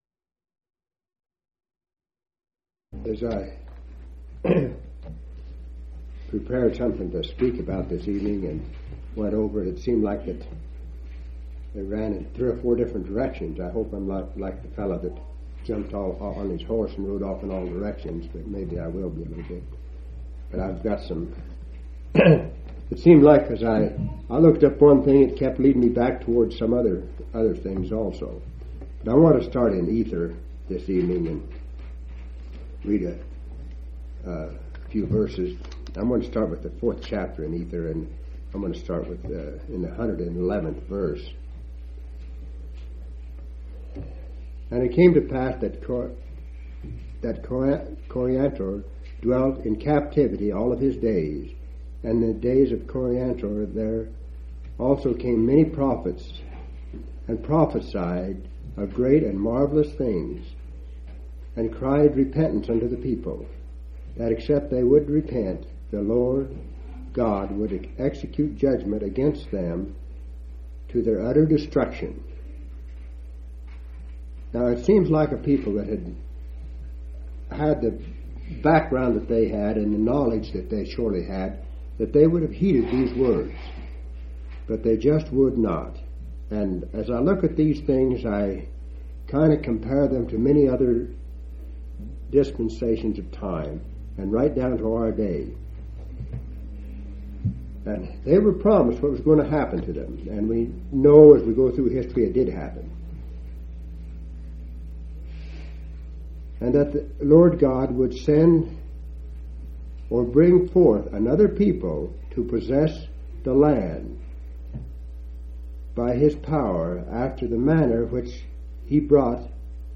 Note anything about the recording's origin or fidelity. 11/1/1987 Location: Grand Junction Local Event